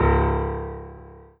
piano-ff-05.wav